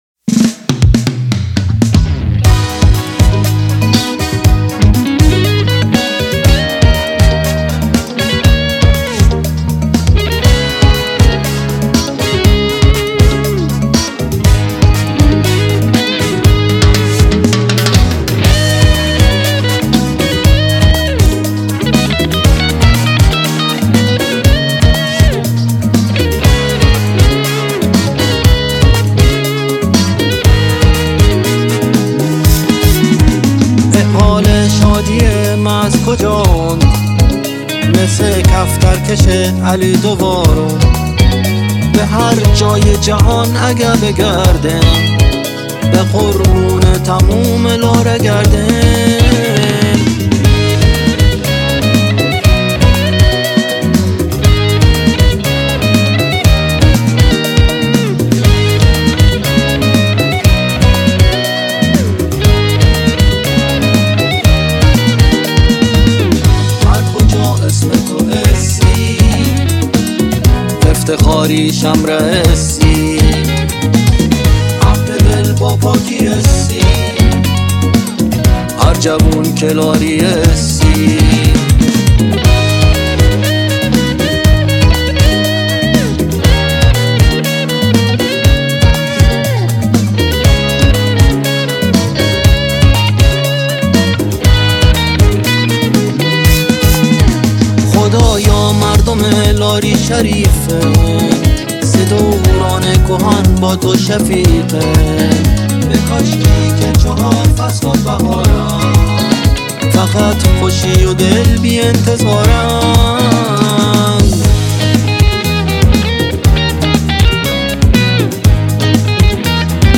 🔸تنظیم و گیتار بیس